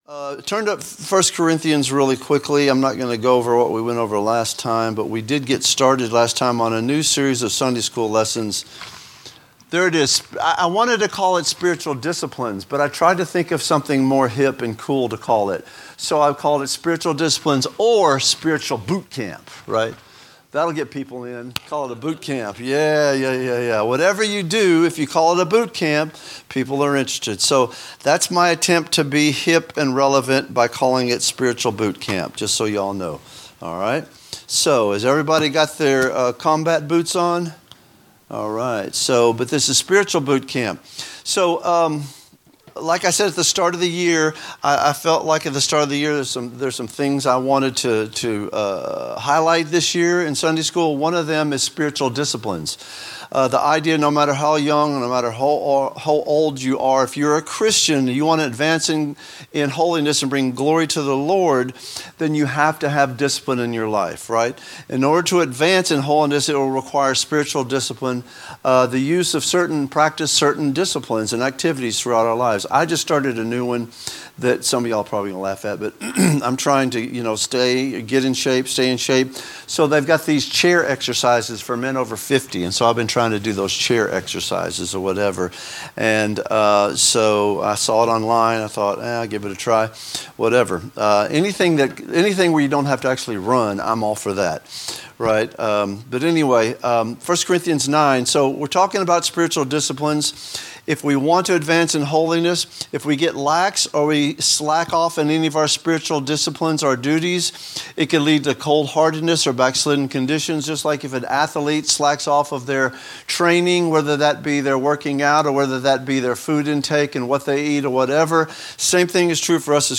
A message from the series "Spiritual Disciplines." A study on what it means for a Christian to live a holy life.